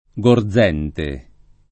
[ g or z$ nte ]